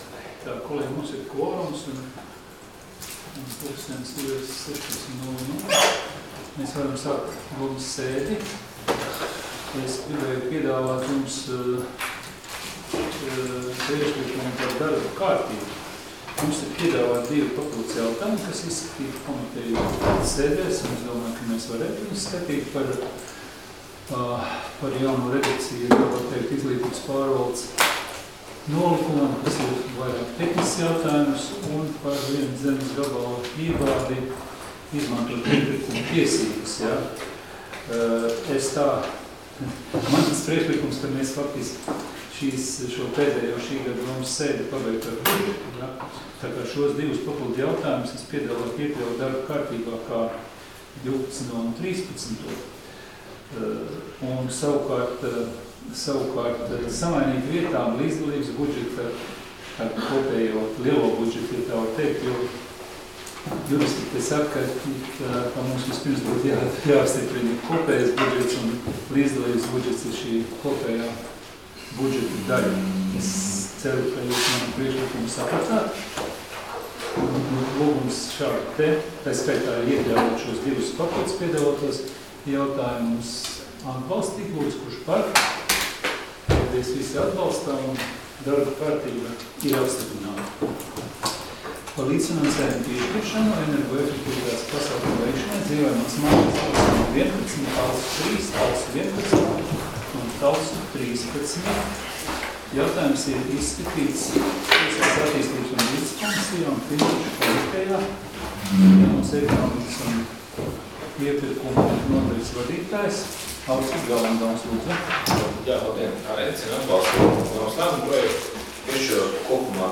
Domes sēdes 29.12.2022. audioieraksts